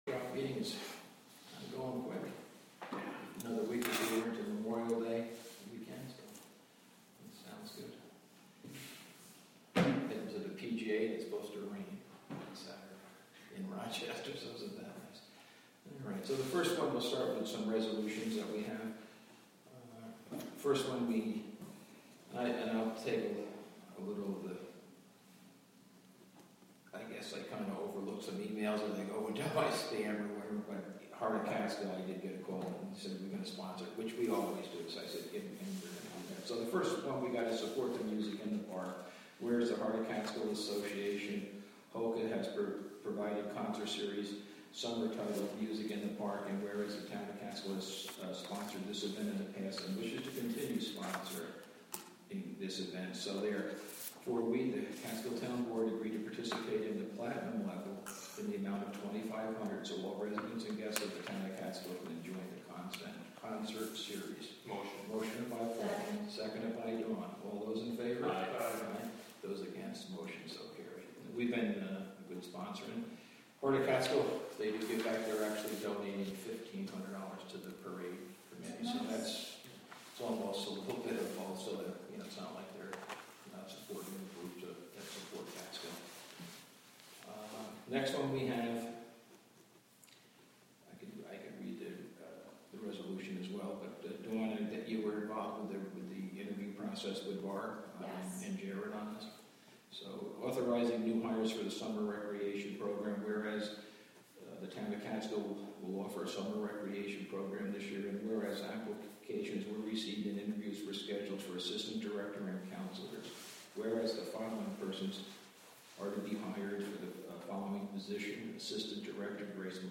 The Town of Catskill holds their monthly meeting.
WGXC is partnering with the Town of Catskill to present live audio streams of public meetings.